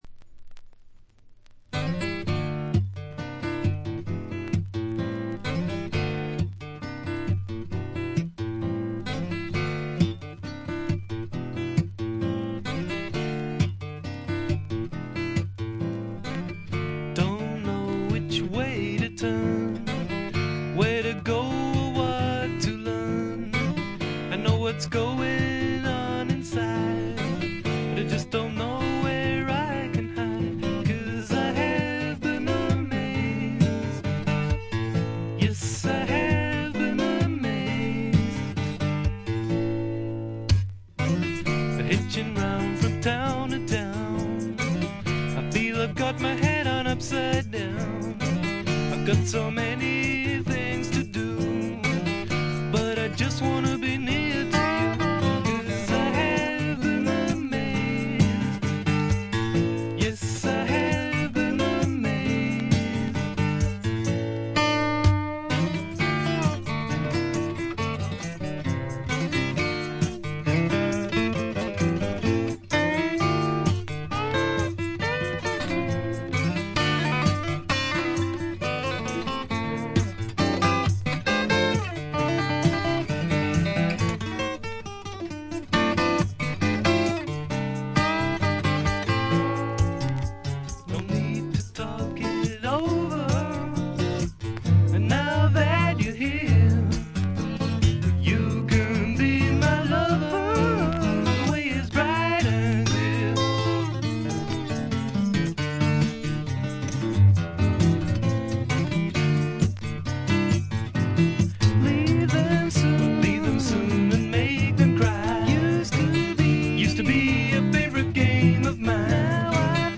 バックグラウンドノイズやチリプチ多め大きめに出ますが凶悪なものはなく普通に鑑賞できるレベルと思います。
いわゆるメローフォーク的な感覚もあります。
試聴曲は現品からの取り込み音源です。